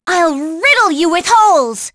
Hanus-Vox_Skill3.wav